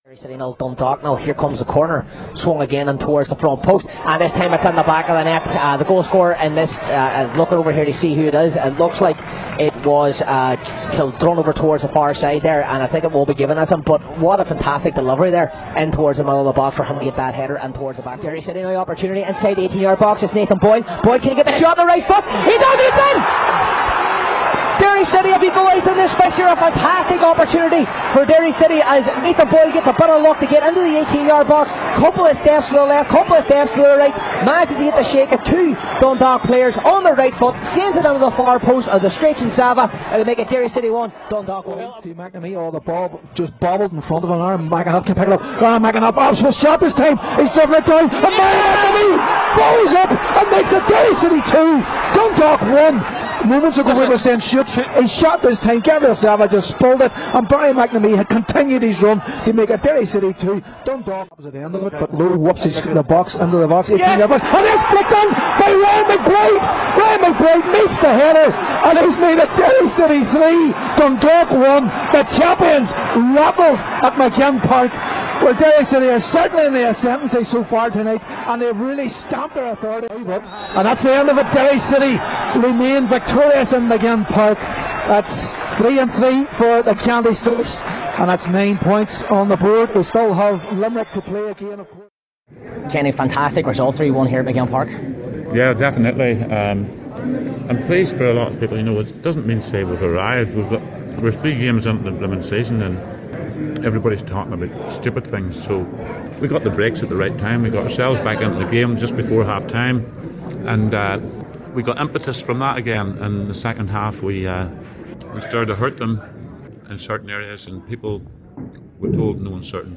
Derry City FC 3 - 1 Dundalk FC Match Highlights
Match Commentators